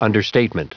Prononciation du mot understatement en anglais (fichier audio)
Prononciation du mot : understatement